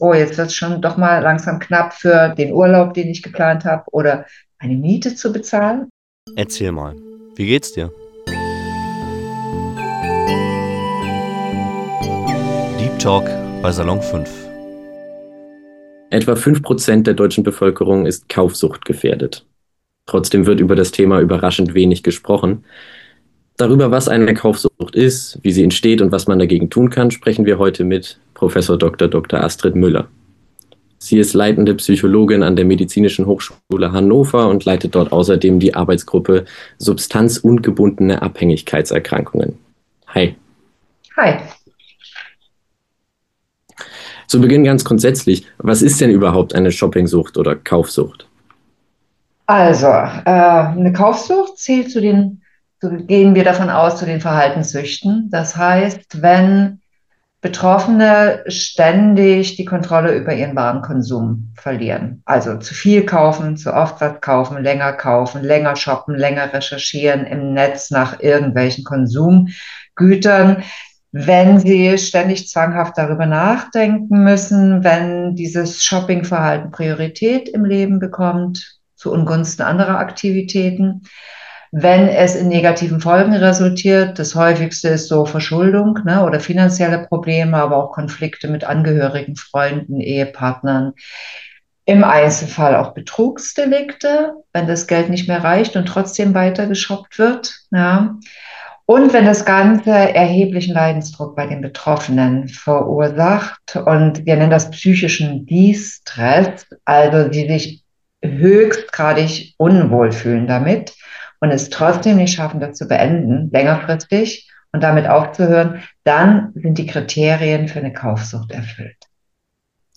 Warum Kaufen süchtig machen kann – Interview